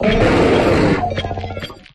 Grito de Ferromole.ogg
Grito_de_Ferromole.ogg